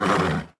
horse_damage.wav